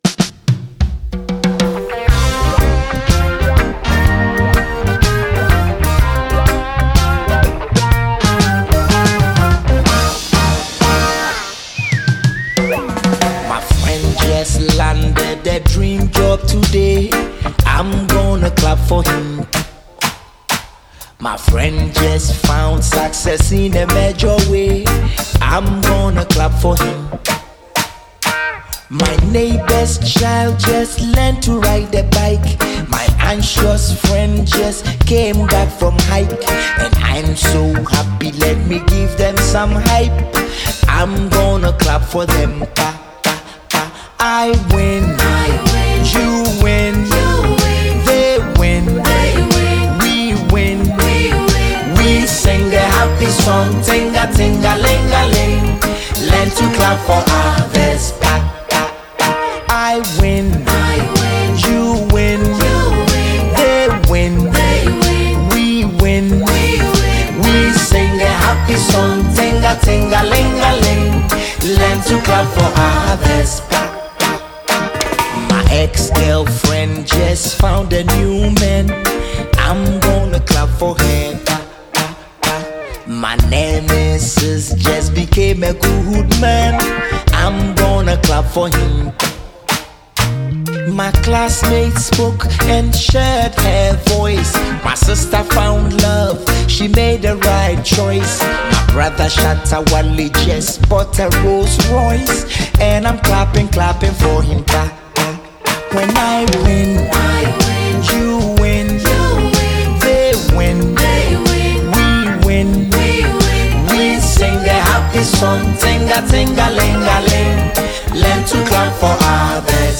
a deeply reflective single
Through poetic lyrics and warm instrumentals